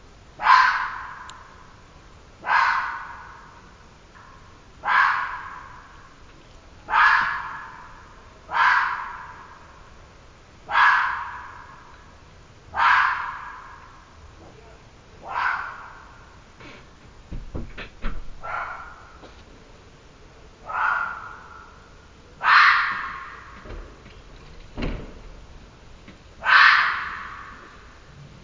Heute war er wiedermal anwesend und schrie fast 10 Minuten herum…
Aber das folgende, seltsam anmutende Gebell konnte man zu fast jeder Zeit vernehmen, nahm aber niemals jenes Tierchen war, welches diese Geräusche ausstieß.
Fox.mp3